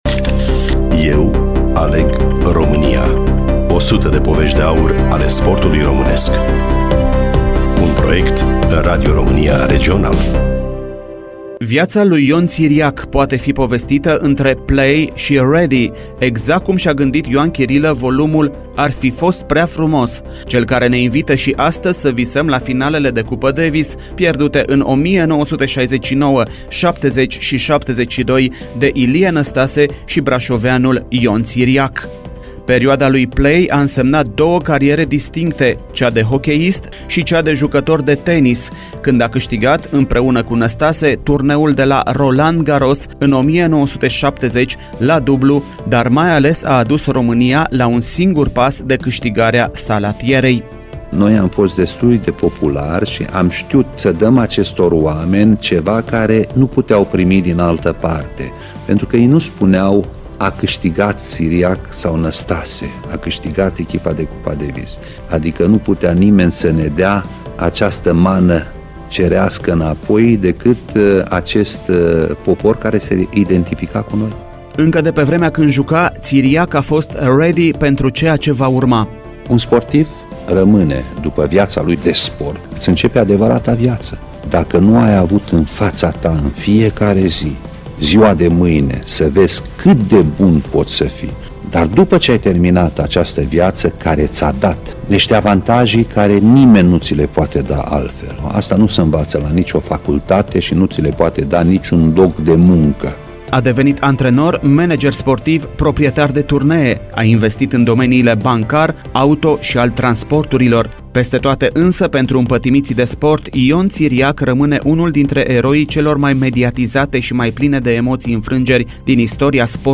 Studiul Radio Romania Timisoara